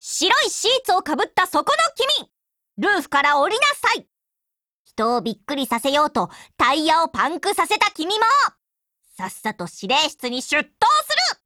贡献 ） 协议：Copyright，其他分类： 分类:语音 、 分类:少女前线:P2000 您不可以覆盖此文件。